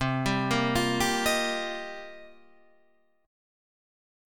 Cm11 chord